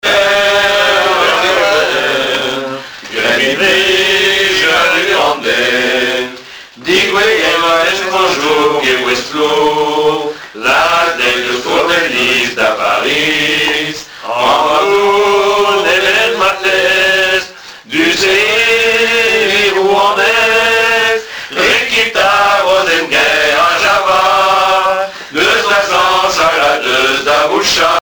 Genre strophique
Témoignages et chansons
Pièce musicale inédite